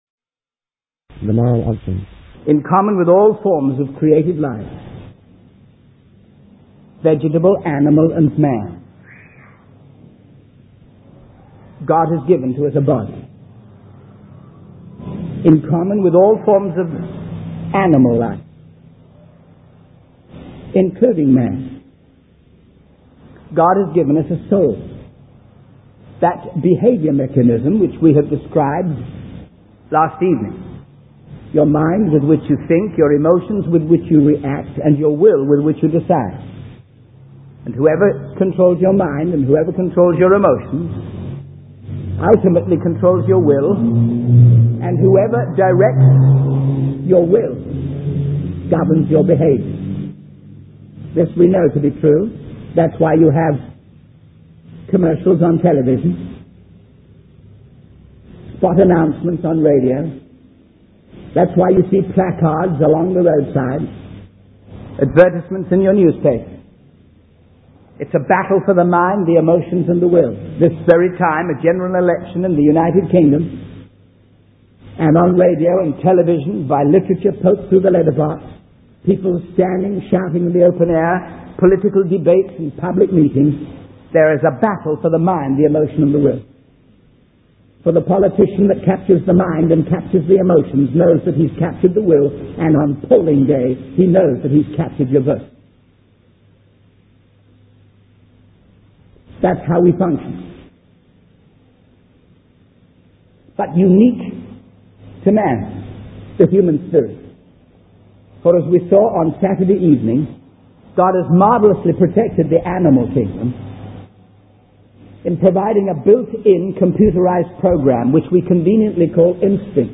Ultimately, the sermon calls for a genuine relationship with God that transforms ou